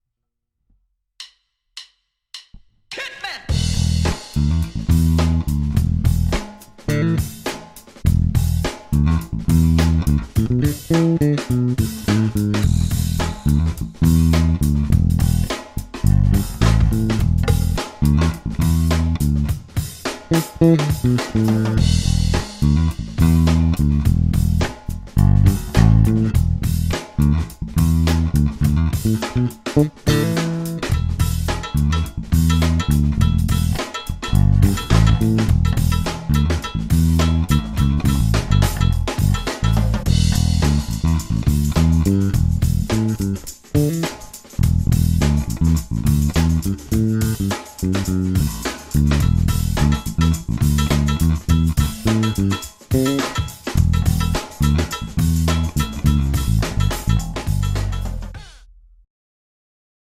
V ukázkách je slyšet různý setup výšky snímačů. 1,3 a 4 jsou na nový setup, který jsem si dělal při hraní ve zkušebně. 2 je původní, podle mě lepší na nahrávání do mixu.
Ukázka 4 - oba snímače, clona plně otevřena